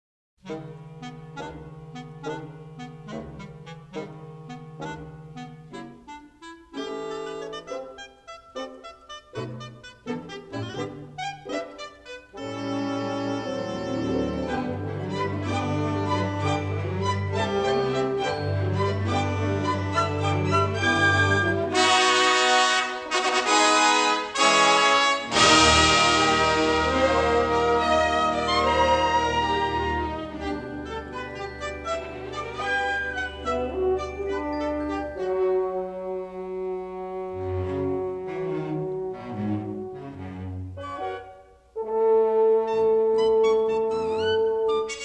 in stereo sound